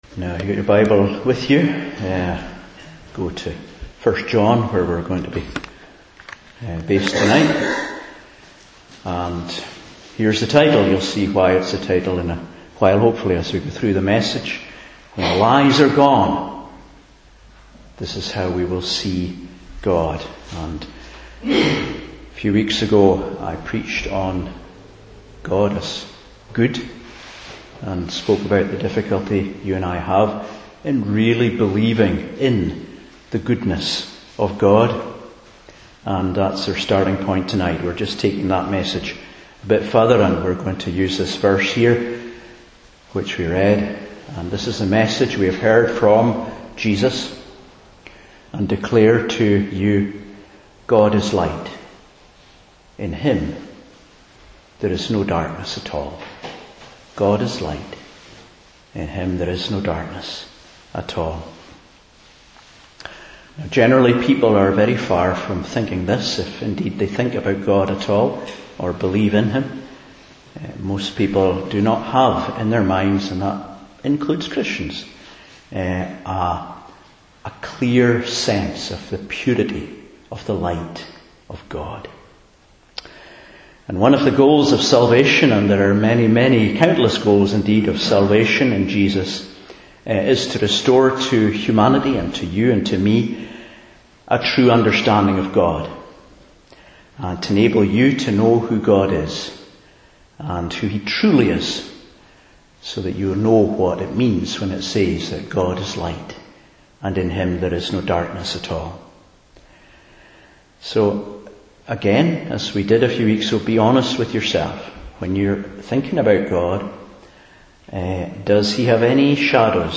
1 John 1:1-2:6 Service Type: Evening Service God is Light.